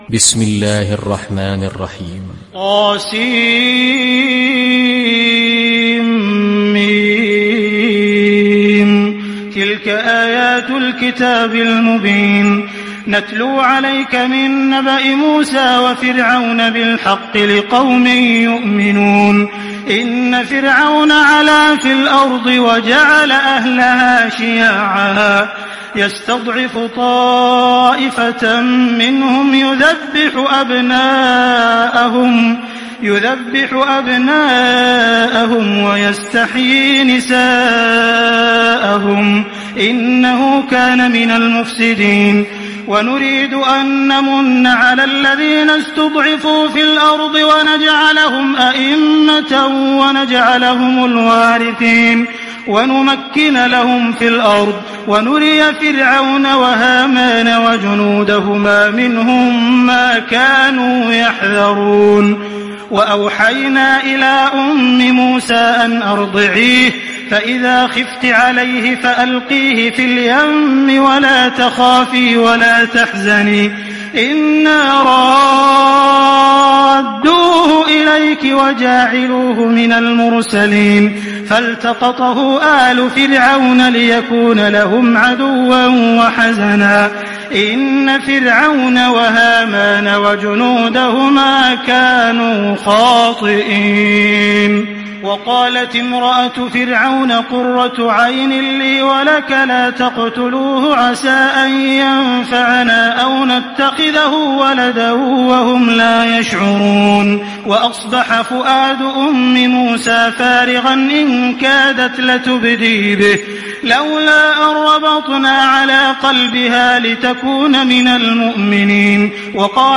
تحميل سورة القصص mp3 بصوت عبد الرحمن السديس برواية حفص عن عاصم, تحميل استماع القرآن الكريم على الجوال mp3 كاملا بروابط مباشرة وسريعة